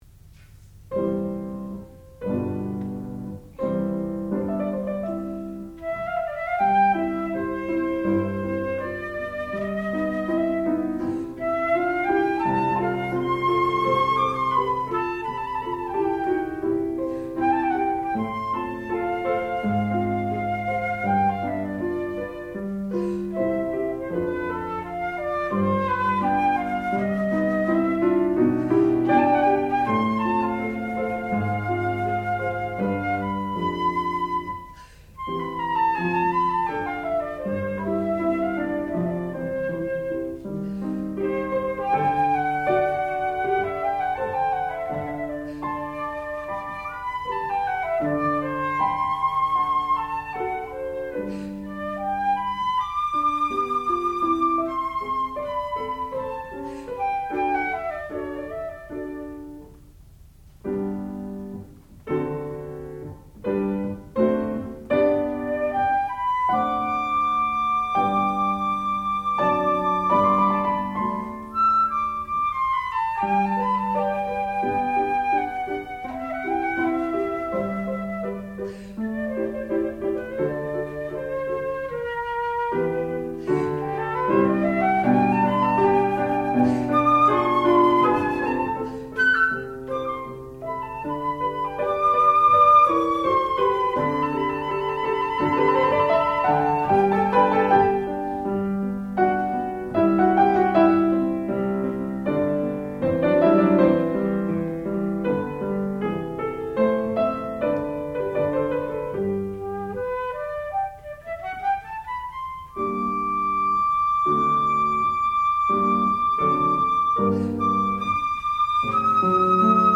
sound recording-musical
classical music
Advanced Recital
flute